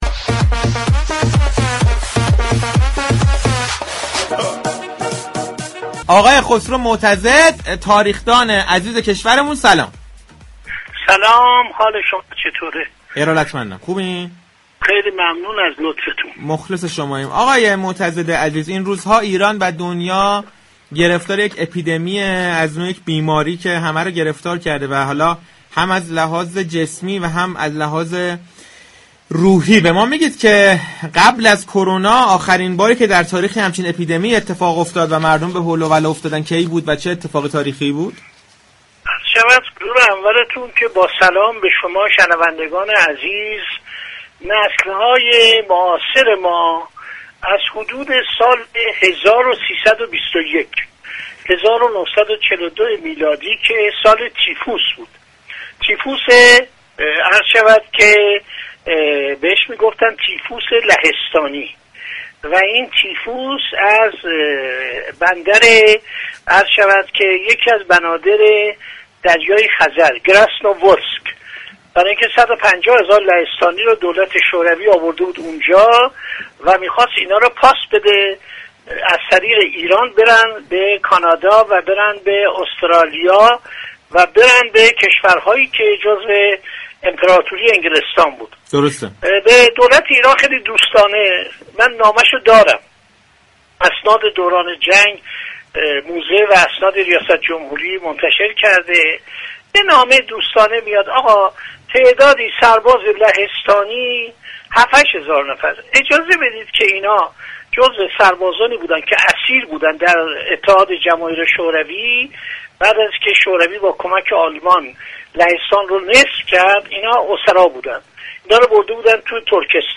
خسرو معتضد، تاریخ‌نویس معاصر كشورمان كه از چهره‌های شناخته شده‌ی رسانه‌ی ملی است به مخاطبان رادیو تهران توصیه كرد، برای پی‌‌گیری اخبار مربوط به كرونا، تنها به رسانه‌های معتبر اعتماد كنند.